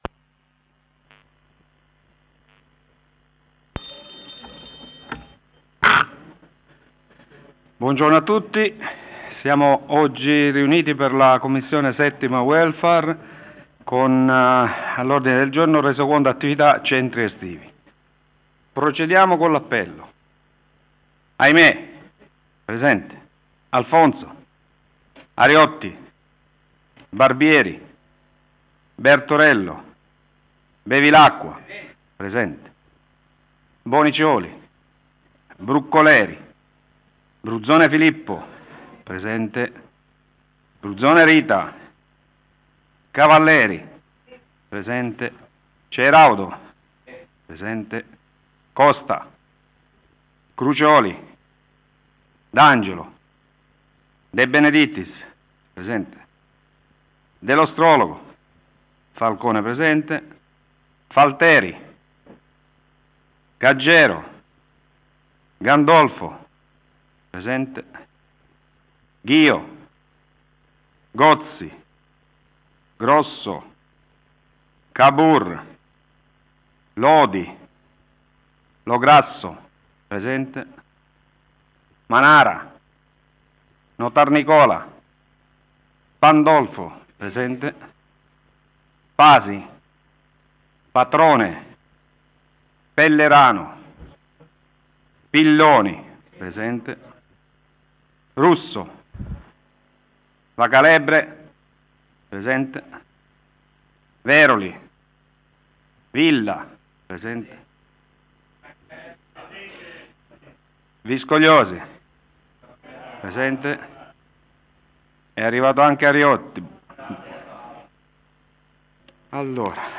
Commissione consiliare o Consiglio Comunale: 7 - Welfare
Luogo: Presso la Sala Consiliare di Palazzo Tursi - Albini Ordine del giorno Resoconto attività centri estivi